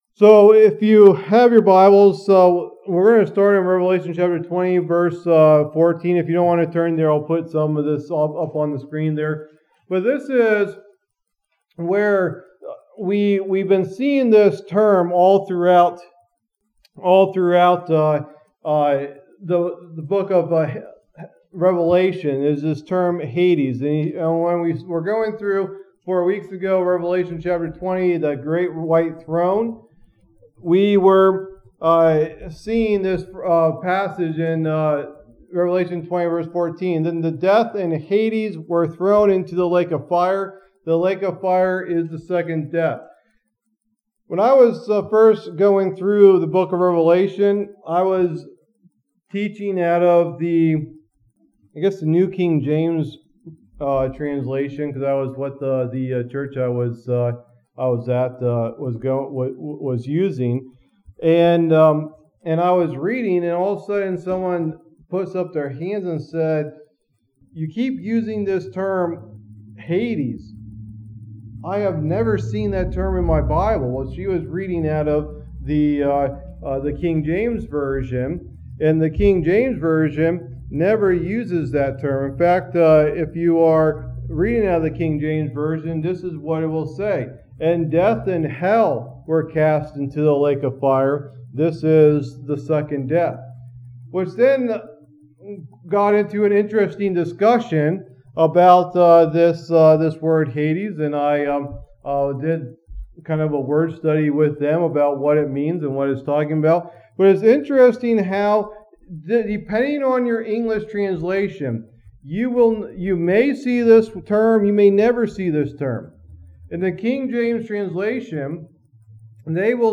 Message #49 in the "Book of Revelation" teaching series